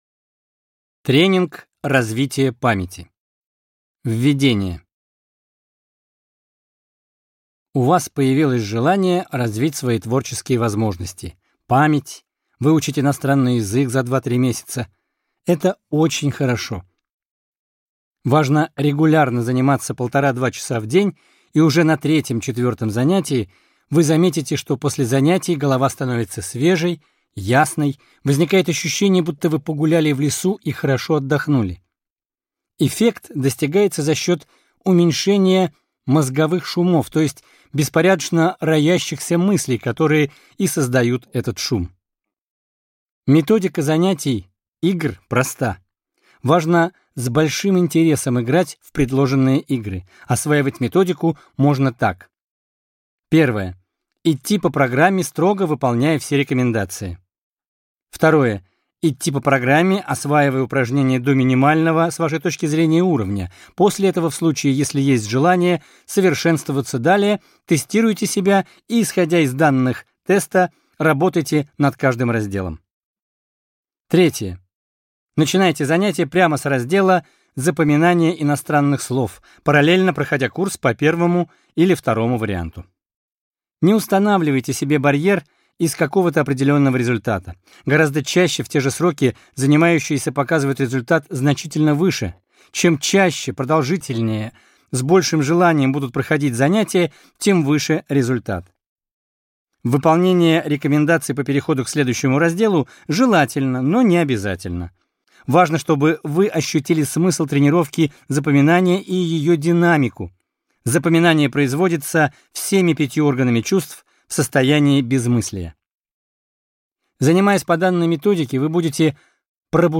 Аудиокнига Тренинг развития памяти | Библиотека аудиокниг